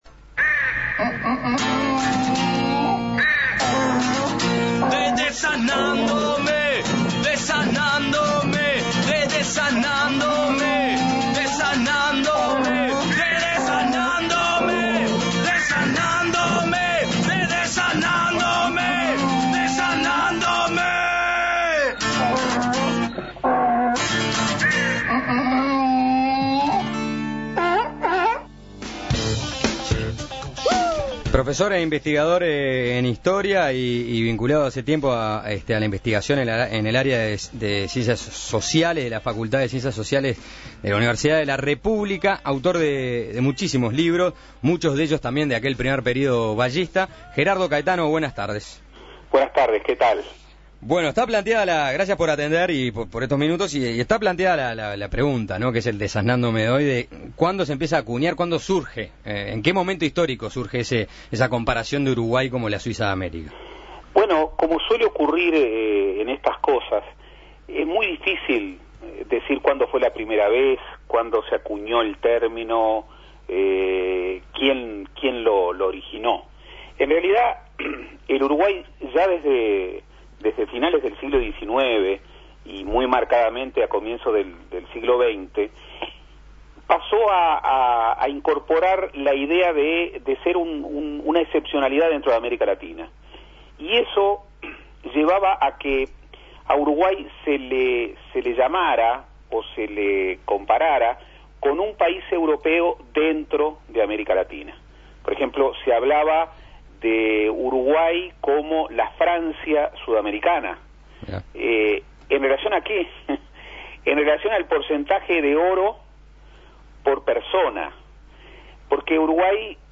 Suena Tremendo se contactó con el politólogo e historiador Gerardo Caetano para desasnarse de la duda